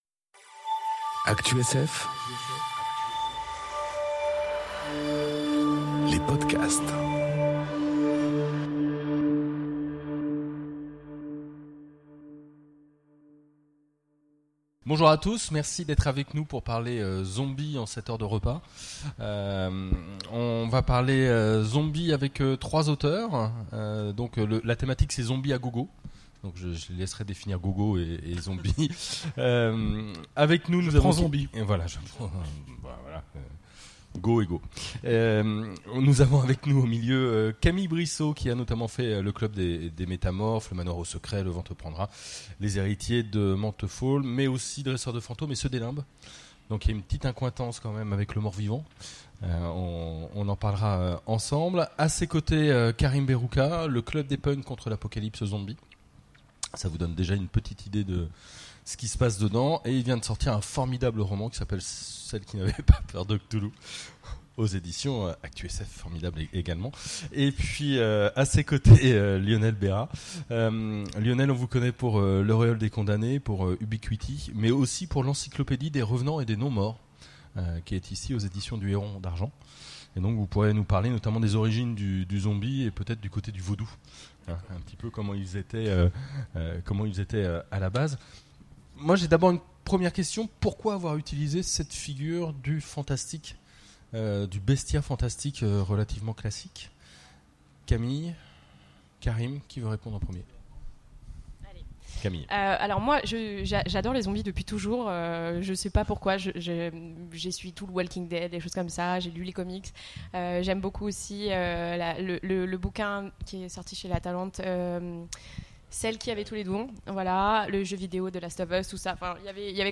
Conférence Zombies à gogo... Salut les morts-vivants enregistrée aux Imaginales 2018